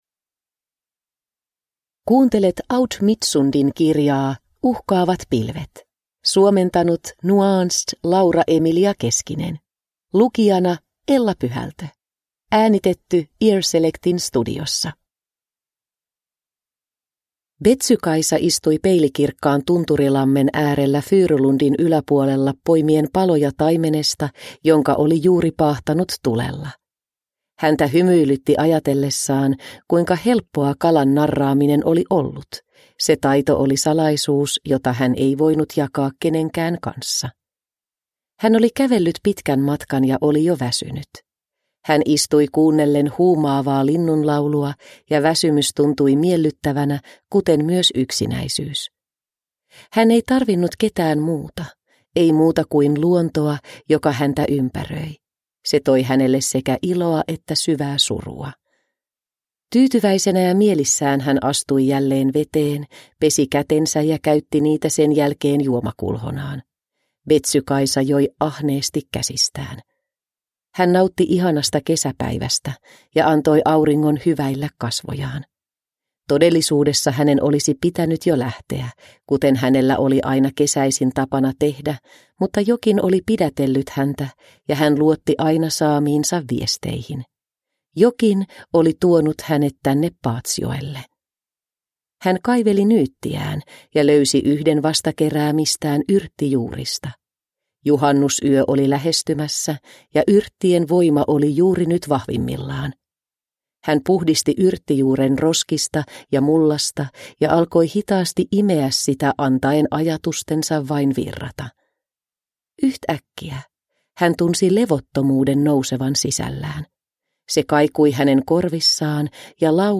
Uhkaavat pilvet – Ljudbok – Laddas ner